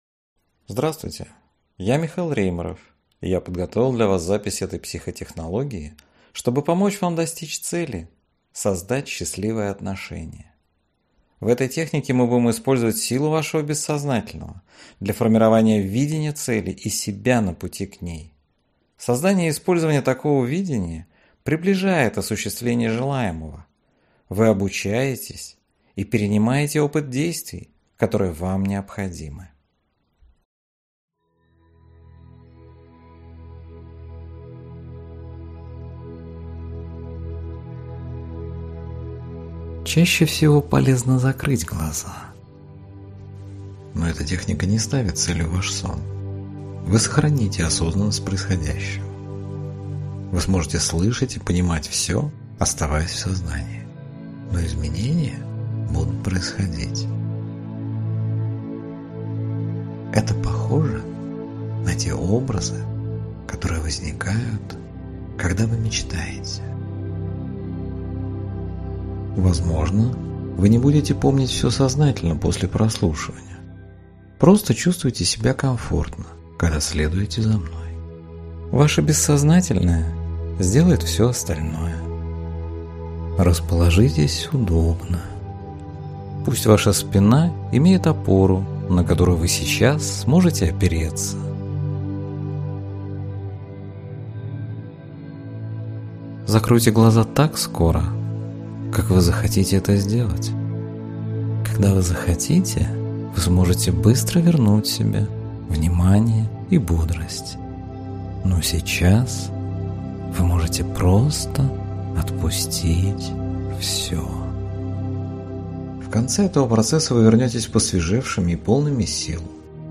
Аудиокнига Счастье без стресса. Как создать крепкие отношения | Библиотека аудиокниг